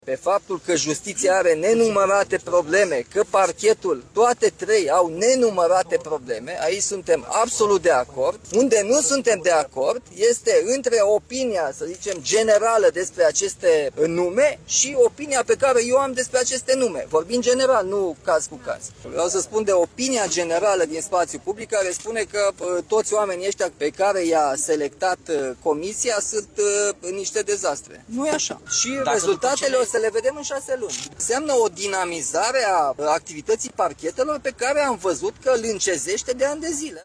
Protest la Cotroceni față de propunerile pentru șefia marilor parchete.
În scurt timp, șeful statului, Nicușor Dan, a ieșit să discute cu protestatarii. El a spus că își va asuma numirile la conducerea parchetelor pe care le va semna și că nu toate persoanele nominalizate de Ministerul Justiției ar fi „niște dezastre”.